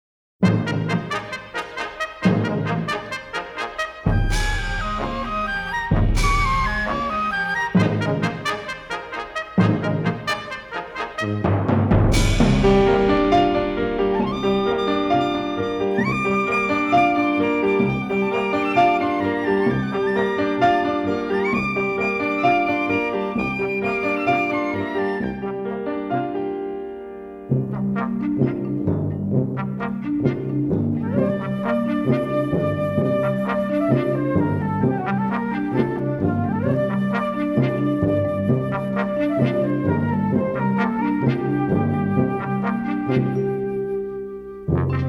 The score is a sensitive gem of melody and Americana
remixed and mastered from the original 1/2" stereo tapes.